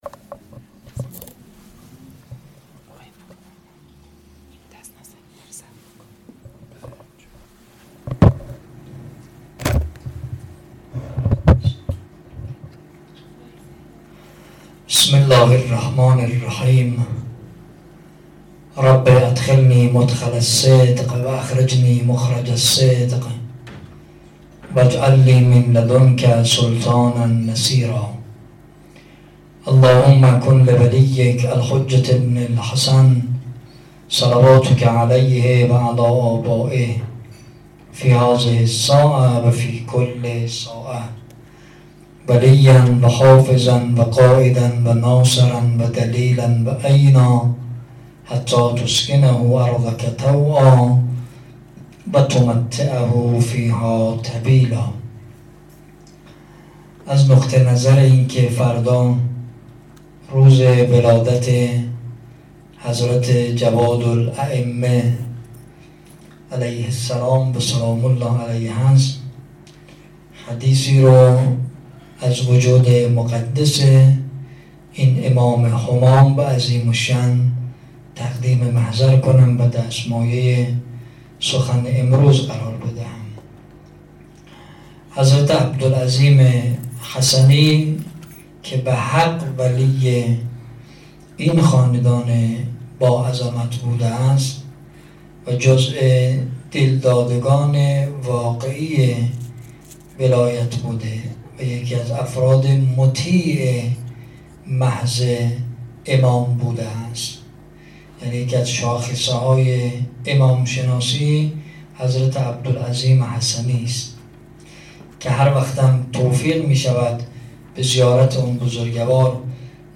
18 فروردين 96 - بیت النور - سخنرانی